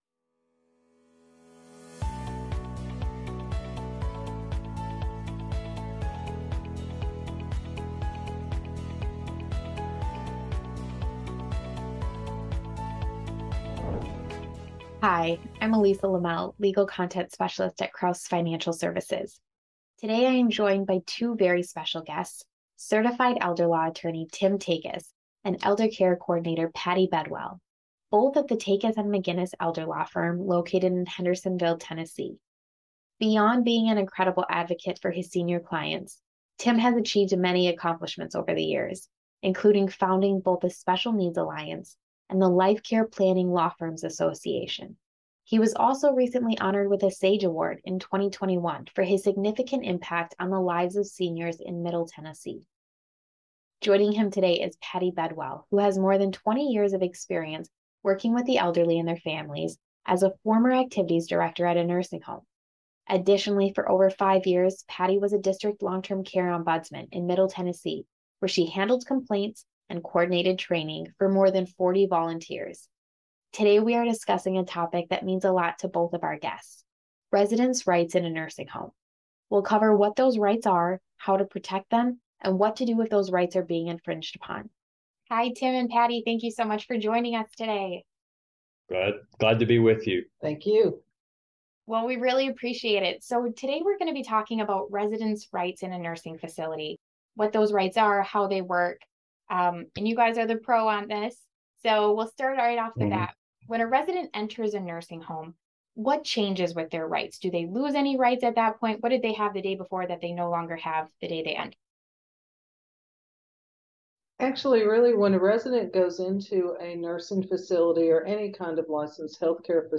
Elder Law Interview